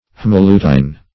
Haemolutein \H[ae]m`o*lu"te*in\ (-l[=u]"t[-e]*[i^]n), n.